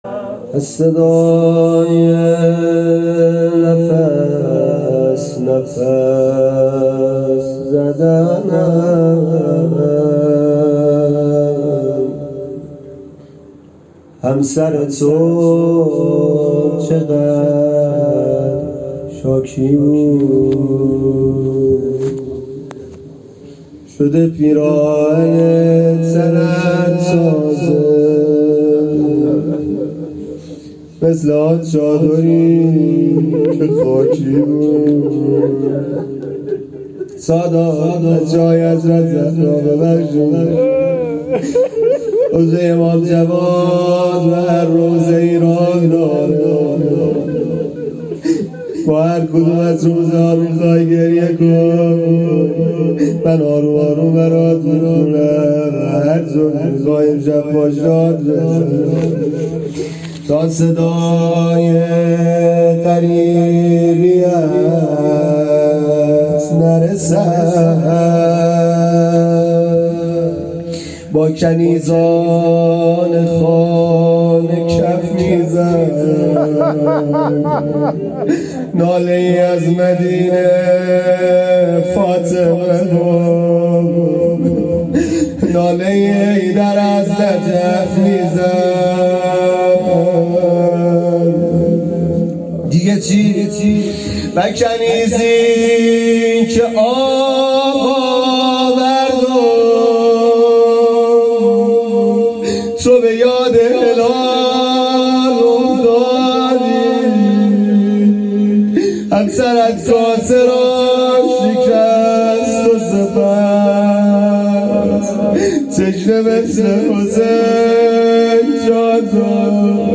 نوحه شهادت جوادالائمه(ع)
نوحه شهادت امام جواد ع